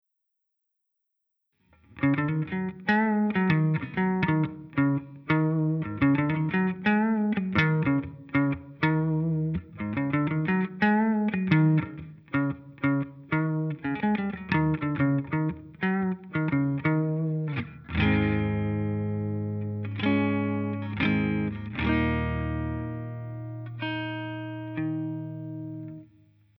Записал для примера свой Washburn hb-15.
Звук специфический, низкий. В примере - играл медиатором, в линию, потом в Bias блюзовый пресет с низким гейном поставил.